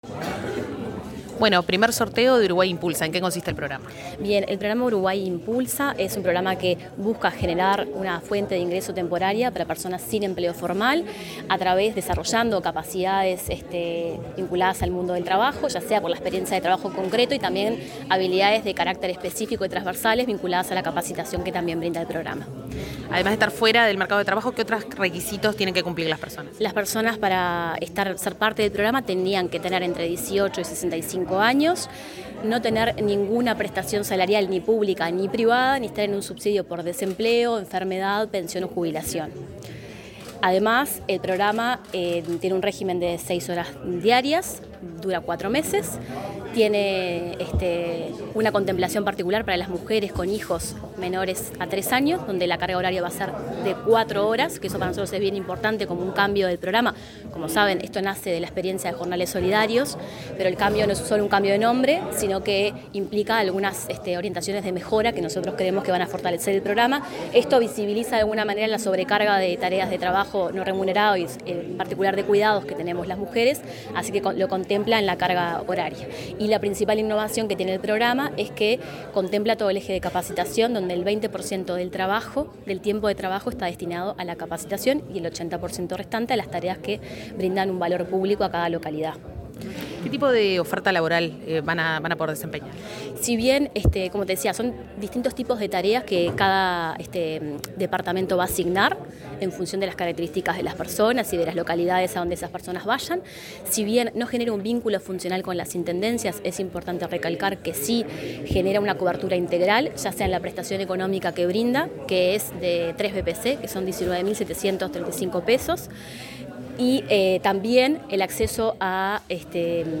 Declaraciones de la subdirectora de nacional de Empleo, Mariana Chiquiar